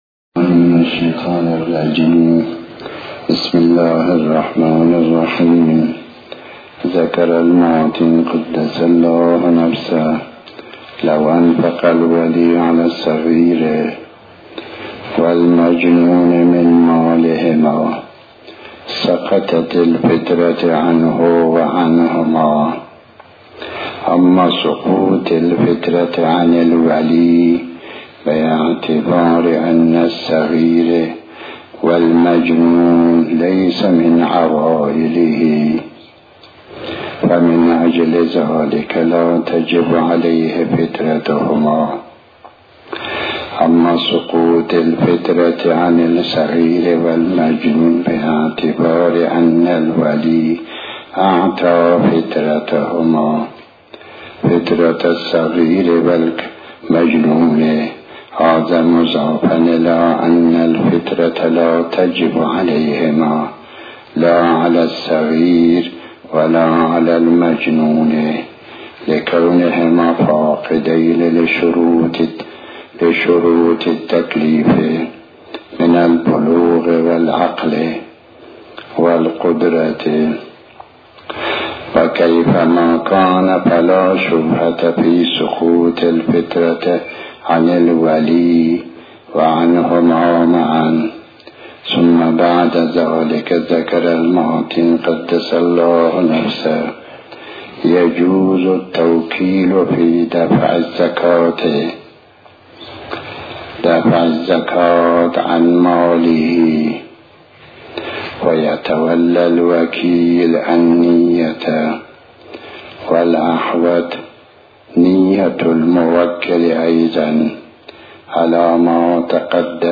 تحمیل آیةالله الشيخ محمداسحاق الفیاض بحث الفقه 38/06/12 بسم الله الرحمن الرحيم الموضوع:- زكاة الفطرة.